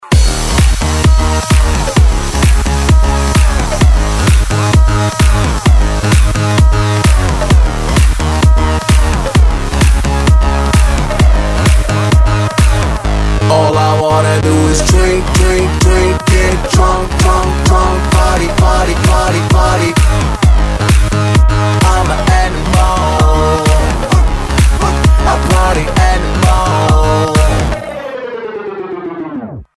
Поп музыка, Eurodance